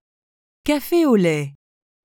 The pronunciation of café au lait is /ka.fe o lɛ/ (IPA), which sounds roughly like “ka-fay oh leh.”